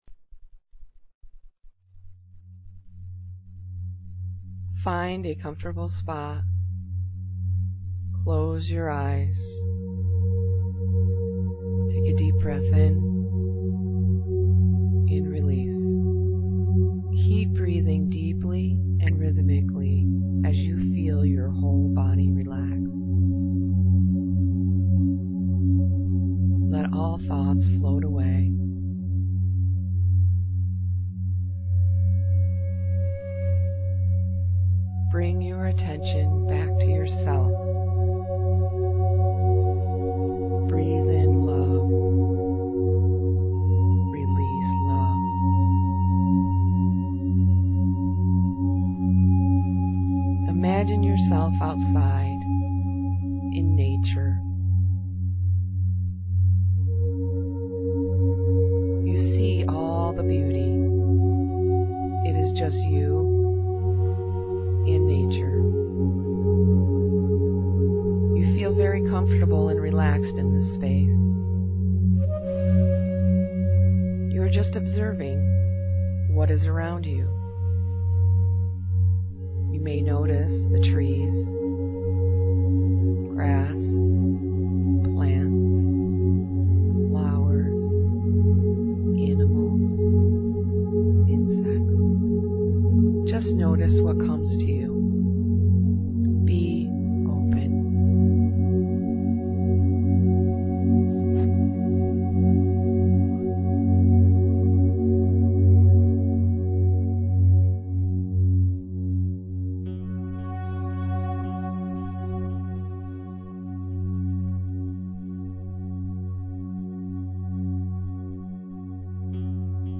Meditations